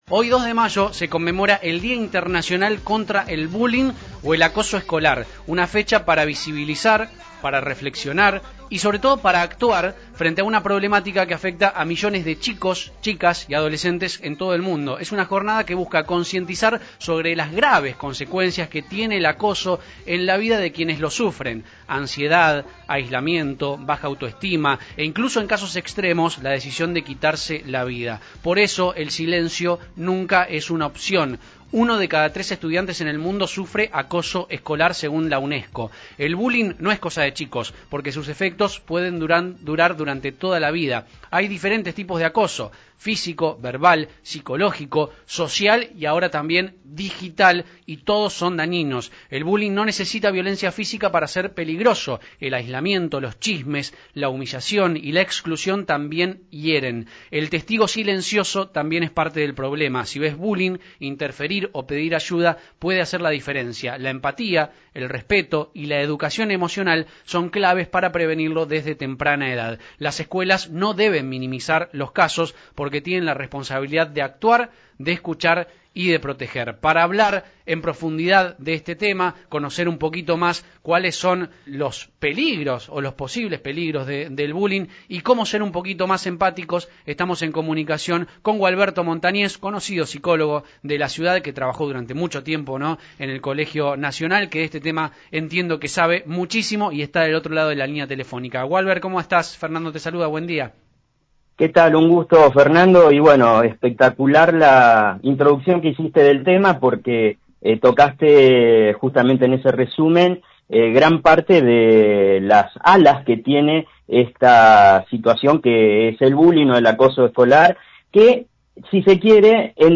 En el programa radial